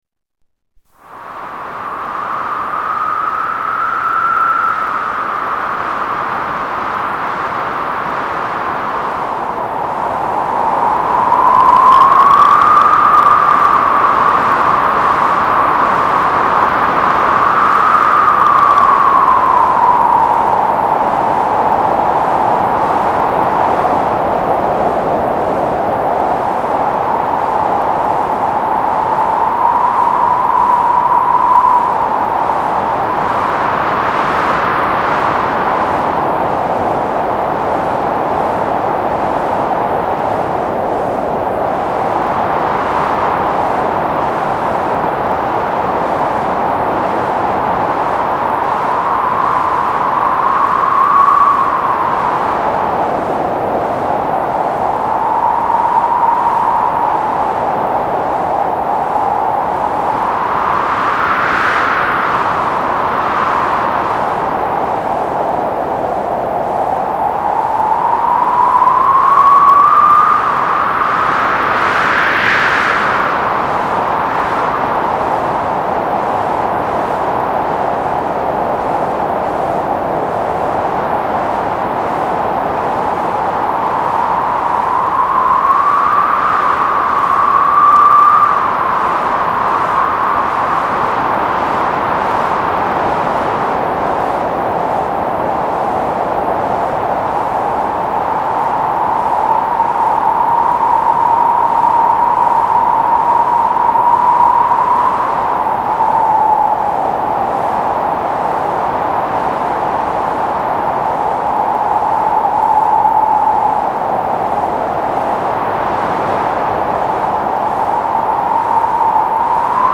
Sonneries » Sons - Effets Sonores » orage bruitage